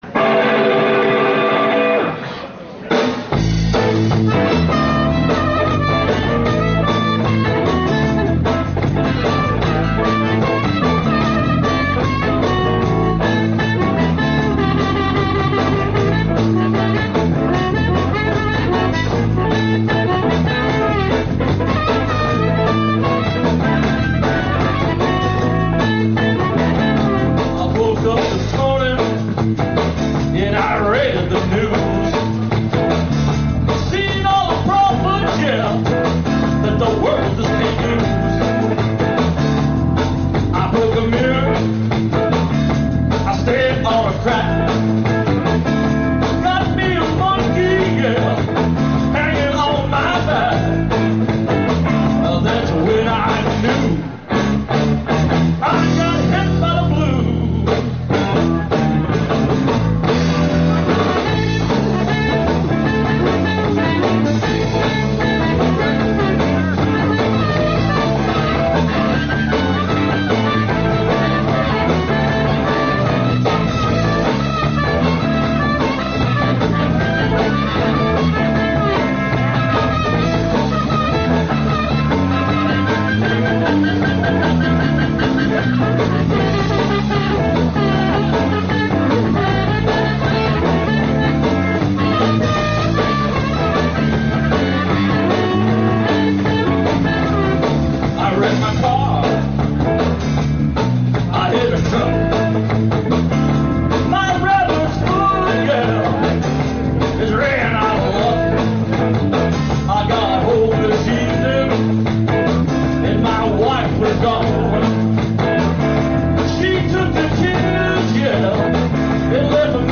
harmonica and lead vocals
guitar and vocals
bass and vocals
blues-rock